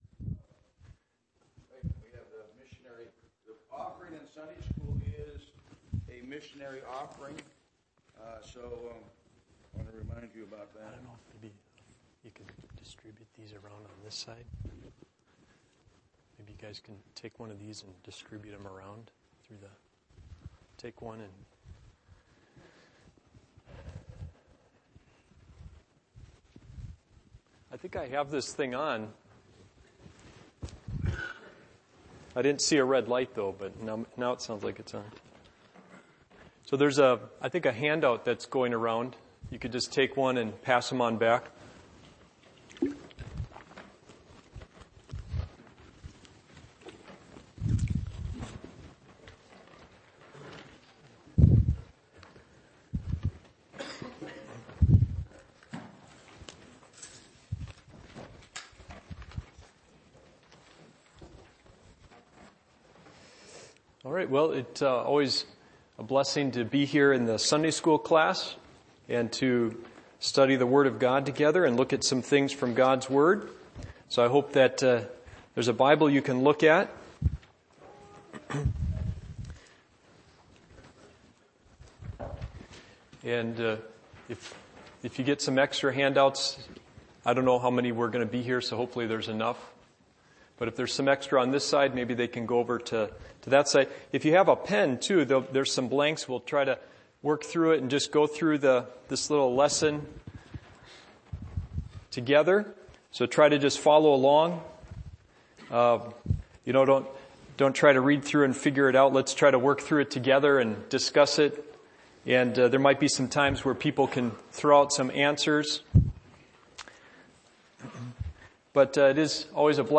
Passage: 1 Peter 2:5 Service Type: Adult Sunday School